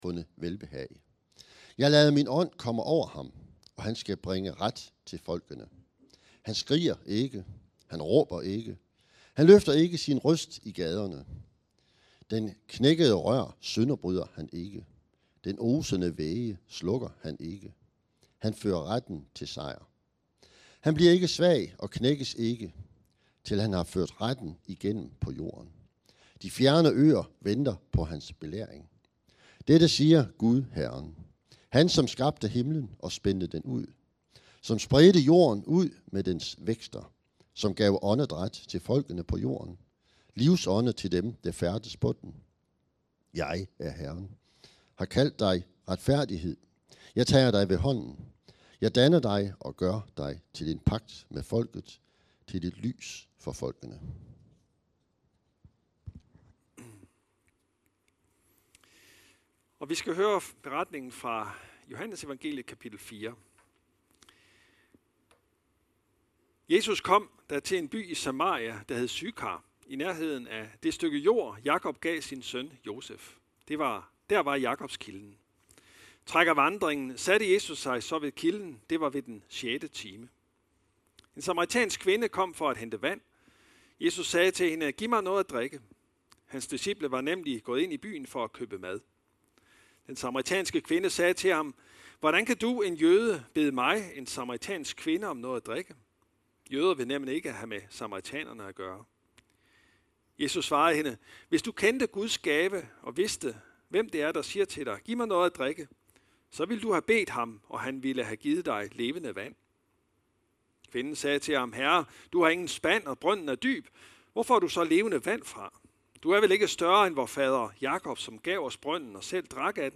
Prædikener En ny begyndelse - Prædiken over den fortabte søn - Herning Bykirke.